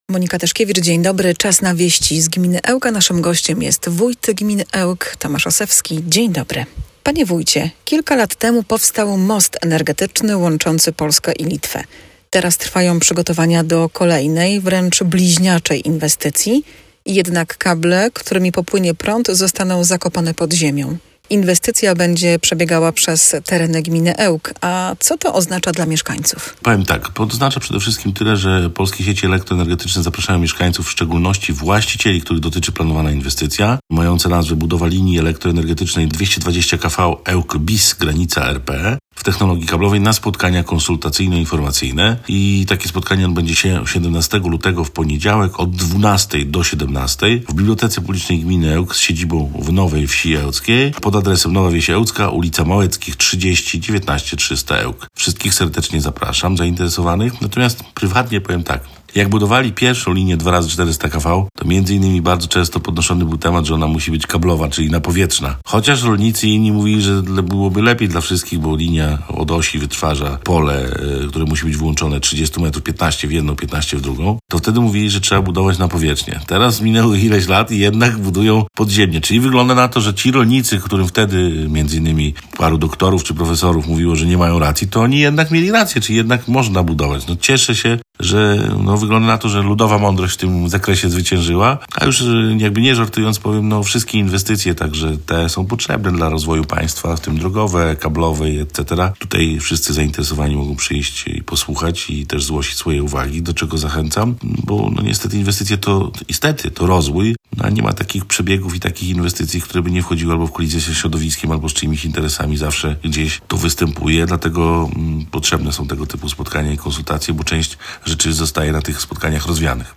Inwestycje elektroenergetyczne na terenie gminy Ełk i pytanie o nową siedzibę ZUG-u. To tematy, które znalazły się w audycji „Wieści z gminy Ełk”. Gościem Radia 5 był Tomasz Osewski, wójt gminy Ełk.